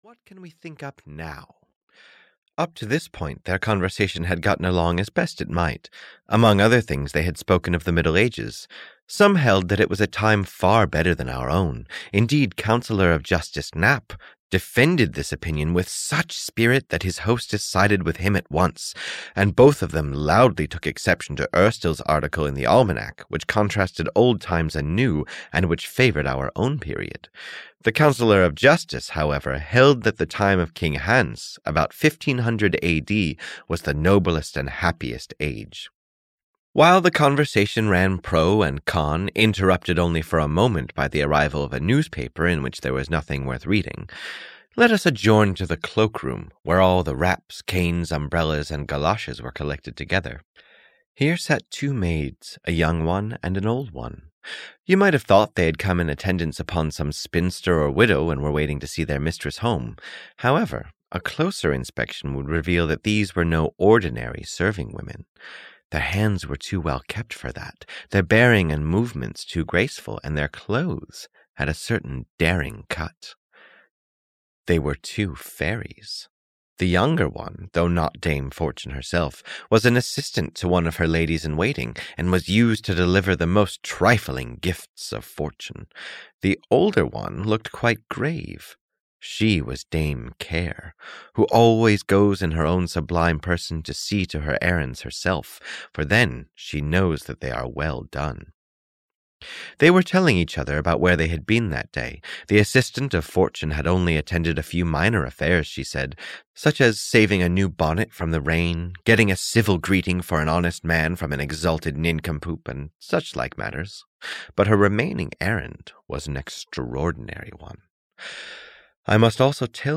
The Galoshes of Fortune (EN) audiokniha
Ukázka z knihy